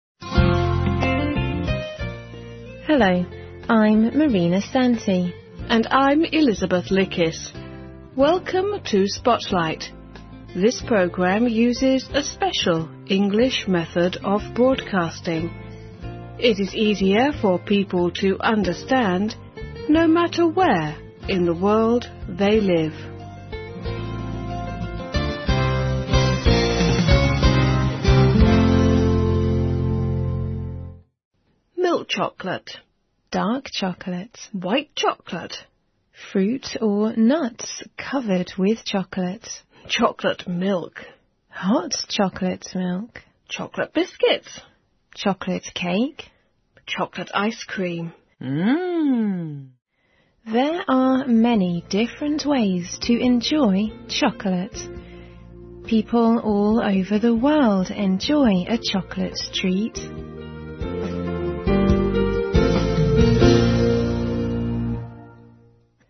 环球慢速英语 第436期:有机巧克力(1)